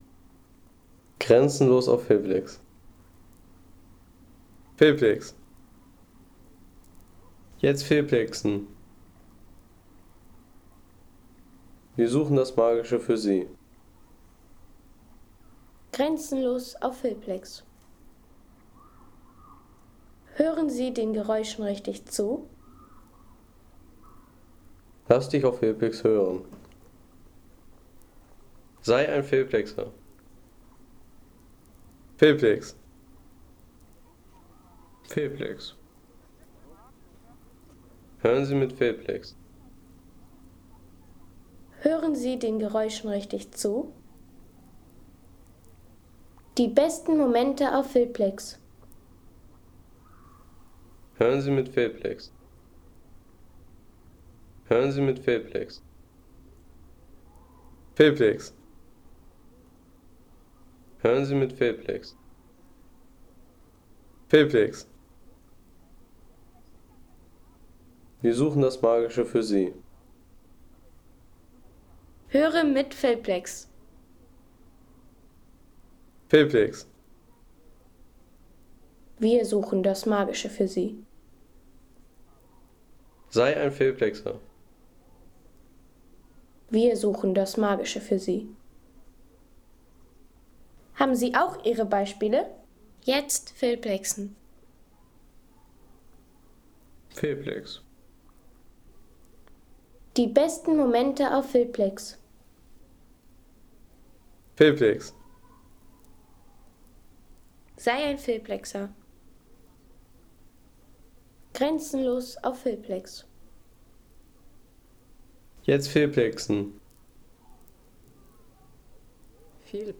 Heiterwanger See Natur-Sound | Feelplex
Ein Seesound mit ruhigem Wasser und sanften Bootswellen
Ruhige Naturkulisse vom Heiterwanger See mit sanften Bootswellen, stillem Wasser und entspannter Uferatmosphäre.
Natürliche Atmosphäre vom Heiterwanger See mit friedlichem Wasser, sanften Wellen und entspannter Uferstimmung für Film und Naturprojekte.